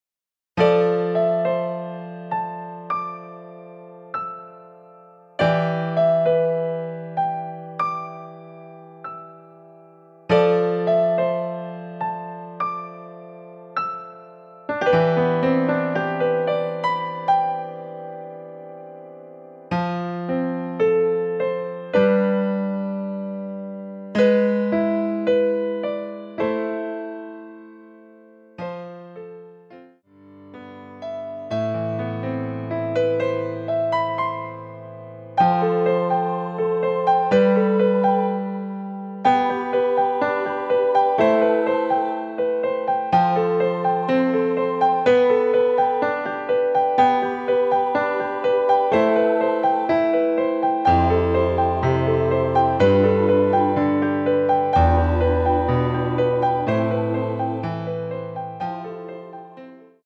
Am
앞부분30초, 뒷부분30초씩 편집해서 올려 드리고 있습니다.
원곡의 보컬 목소리를 MR에 약하게 넣어서 제작한 MR이며
노래 부르 시는 분의 목소리가 크게 들리며 원곡의 목소리는 코러스 처럼 약하게 들리게 됩니다.